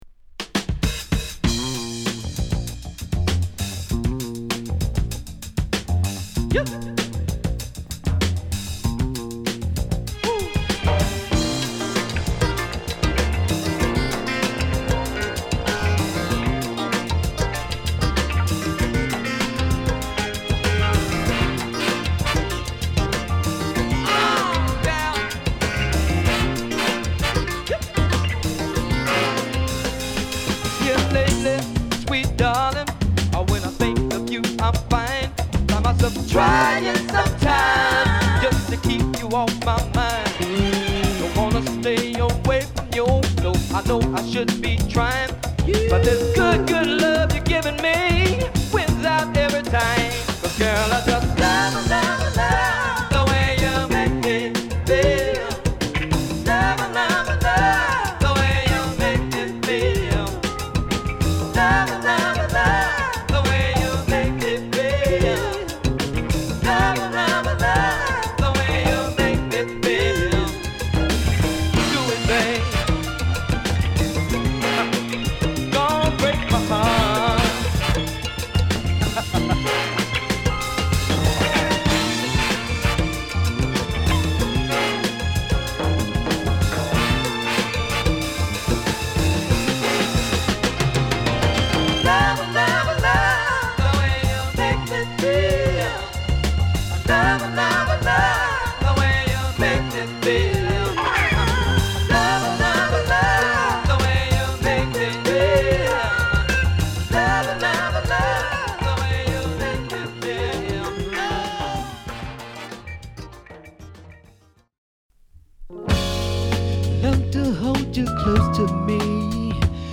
タイトに刻むドラムに爽快な上ものが絡み
ファンキーに弾んだドラムにらしいエレピやホーンが絡み
甘さのなかに切れ味もあるカッコ良い曲を満載！